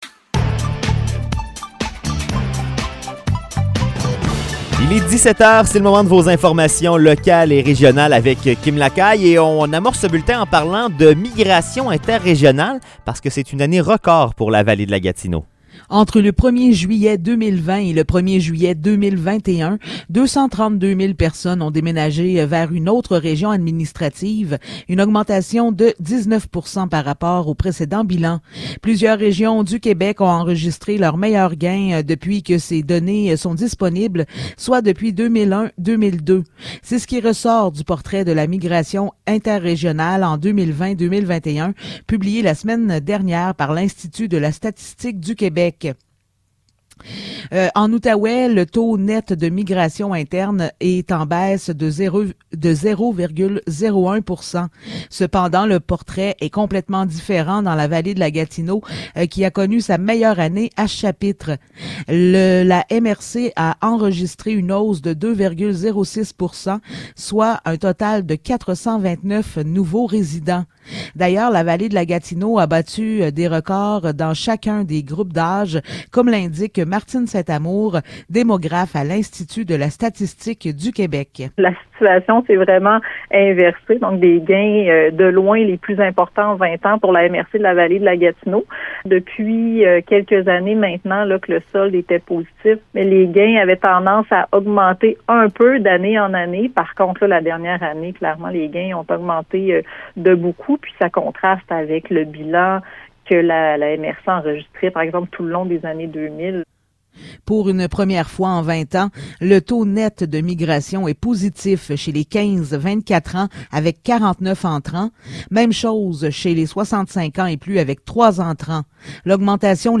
Nouvelles locales - 19 janvier 2022 - 17 h